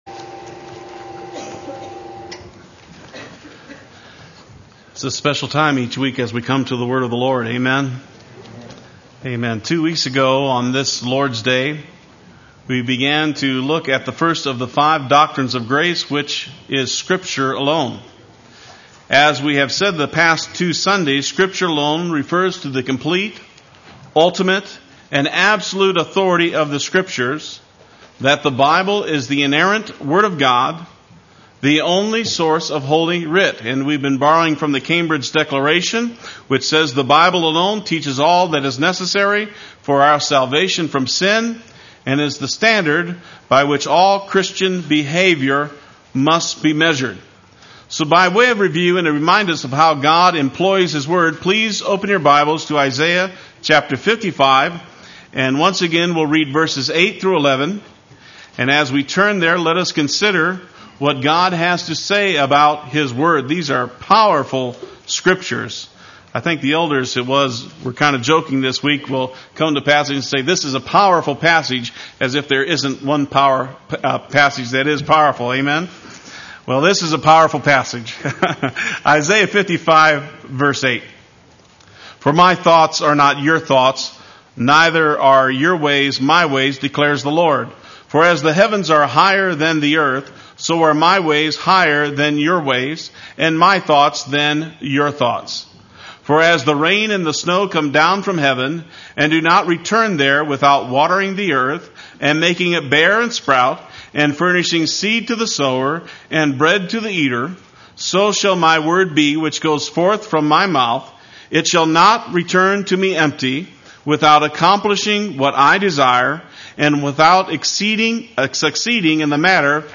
Play Sermon Get HCF Teaching Automatically.
Scripture Alone Part III Sunday Worship